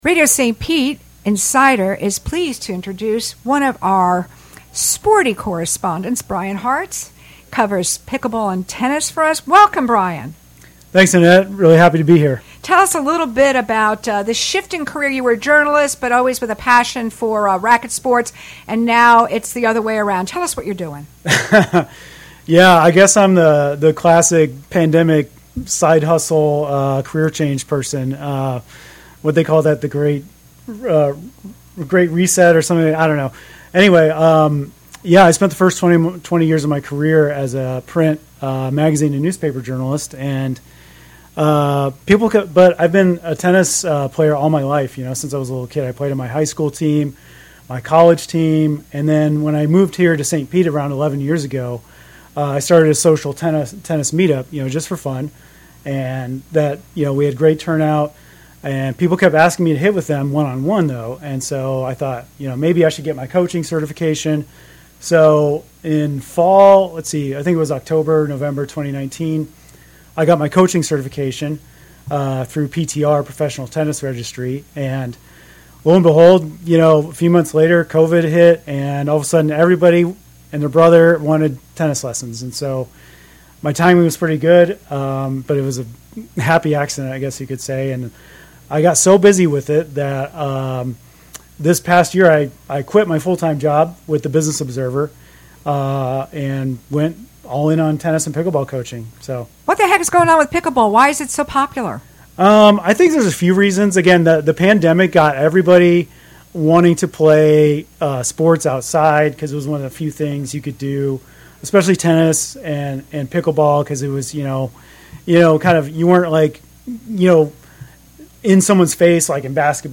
in our downtown studio